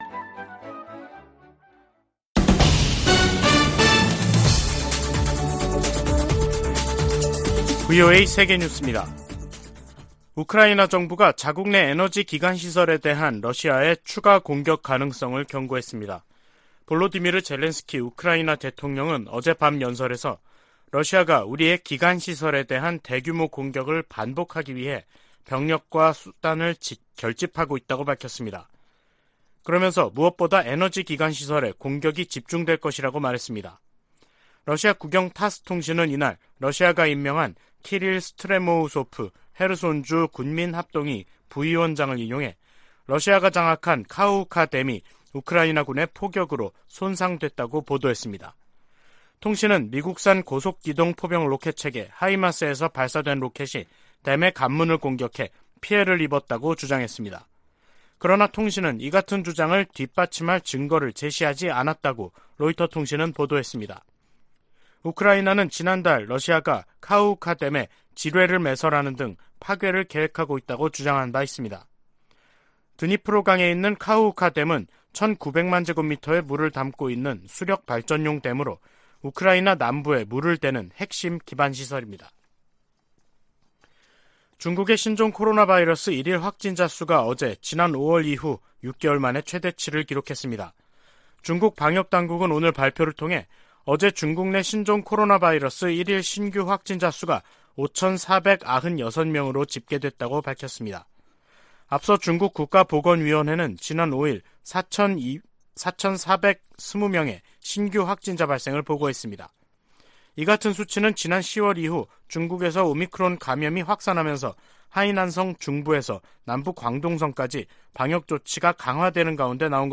VOA 한국어 간판 뉴스 프로그램 '뉴스 투데이', 2022년 11월 7일 3부 방송입니다. 북한이 미한 연합공중훈련 ‘비질런트 스톰’에 대응한 자신들의 군사작전 내용을 대내외 매체를 통해 비교적 상세히 밝혔습니다. 유엔 안보리가 대륙간탄도미사일(ICBM)을 포함한 북한의 최근 탄도미사일 발사에 대응한 공개회의를 개최하고 북한을 강하게 규탄했습니다. 미국은 북한의 계속되는 도발을 우려하며 심각하게 받아들이고 있다고 백악관 고위관리가 밝혔습니다.